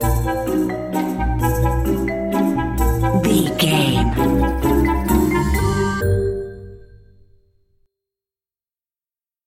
Uplifting
Ionian/Major
D
Slow
flute
oboe
strings
orchestra
cello
double bass
percussion
violin
sleigh bells
silly
circus
cheerful
perky
Light hearted
quirky